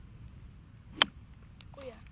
Racquet Sound » racquet
描述：Ball hitting racquet or paddle sound
标签： or hitting paddle Ball sound racquet
声道立体声